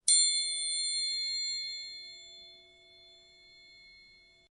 Звуки музыкального треугольника
7. Высокий тон